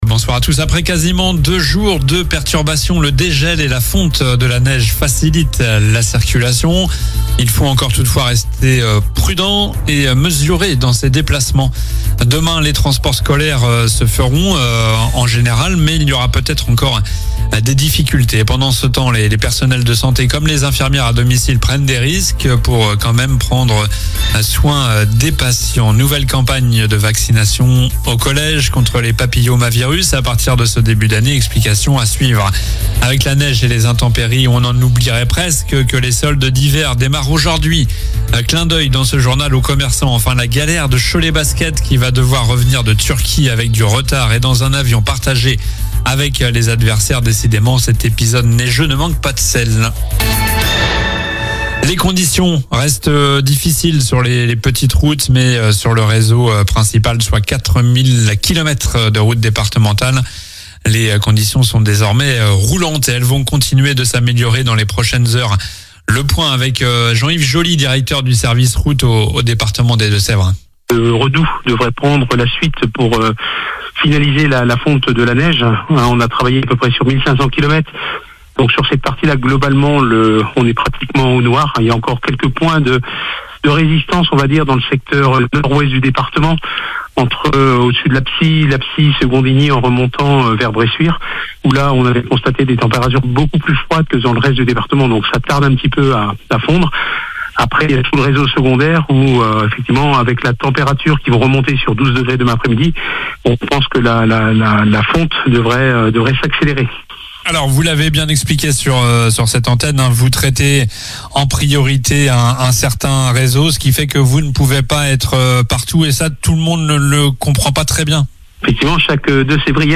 Journal du mercredi 7 janvier (soir)